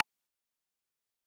keyboard3.mp3